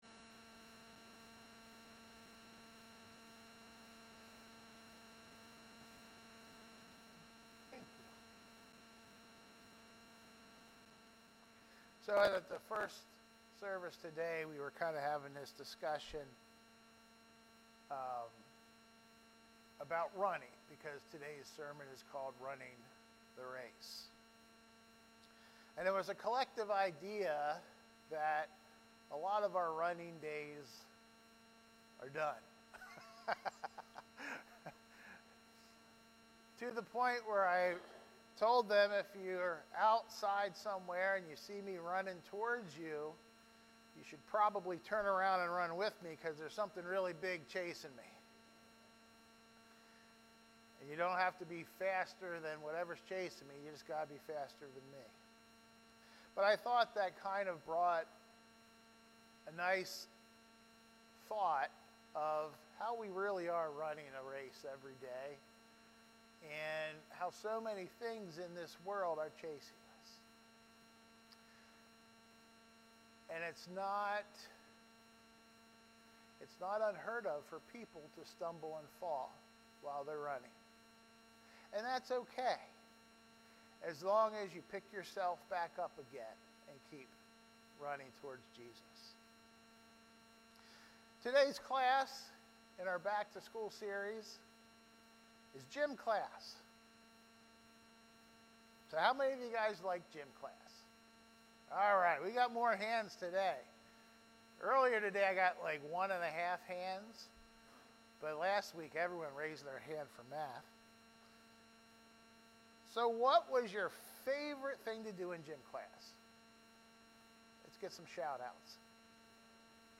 Sermons | Columbia Church of God